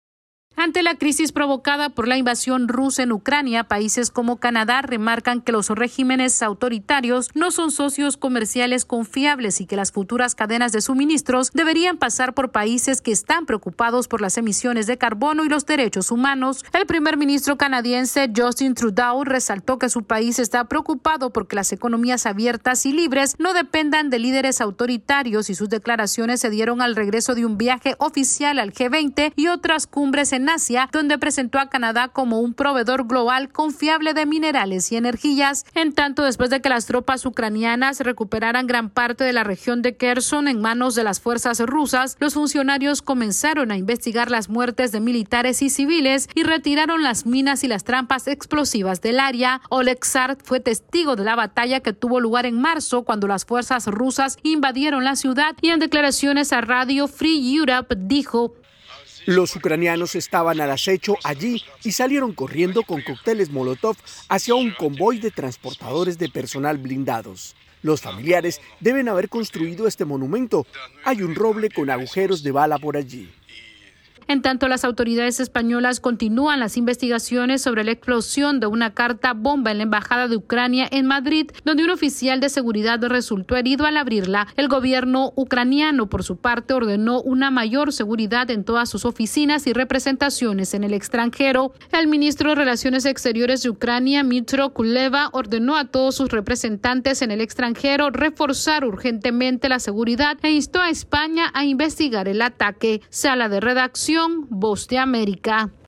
Canadá plantea un cambio en la cadena de suministros ante las implicaciones de la guerra en Ucrania, mientras las autoridades de ese país inician las investigaciones en Kherson. Esta es una actualización de nuestra Sala de Redacción.